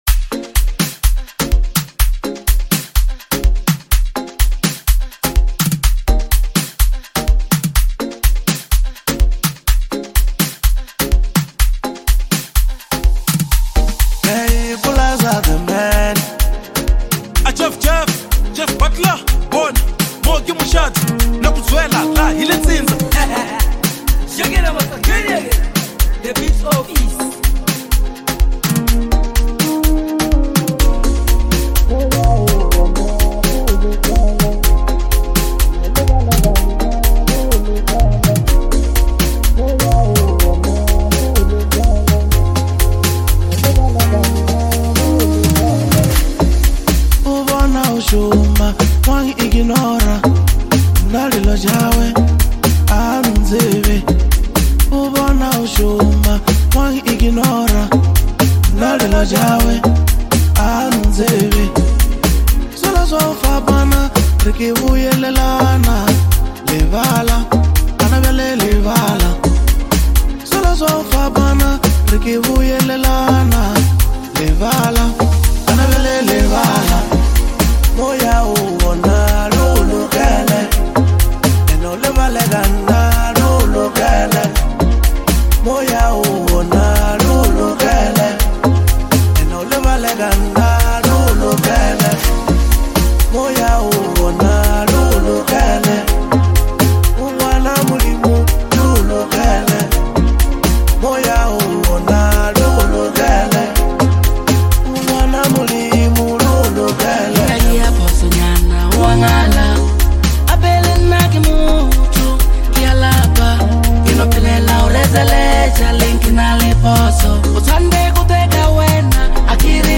deeply spiritual and uplifting lekompo track